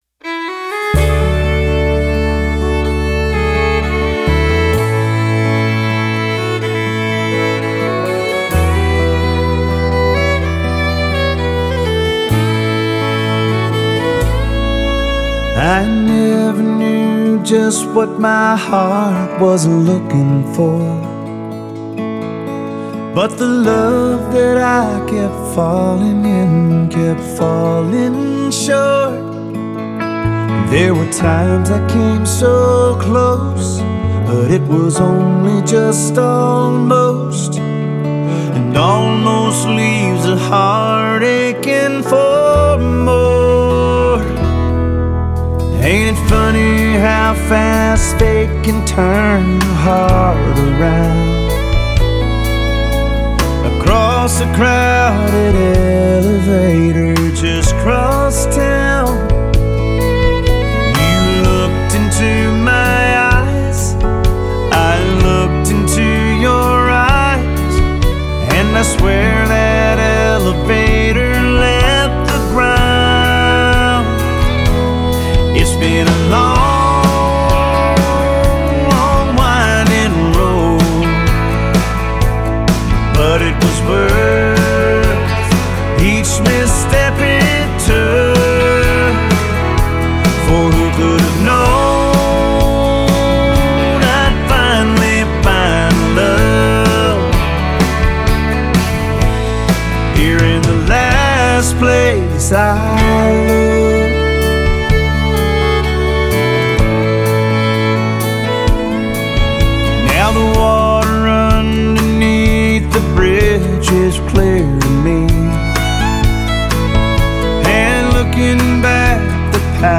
"The Last Place I Looked" (country)